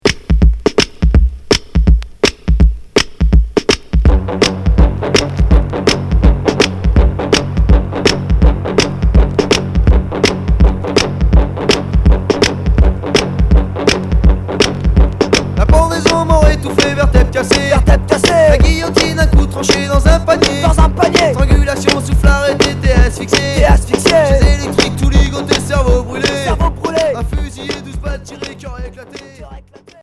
Punk Alternatif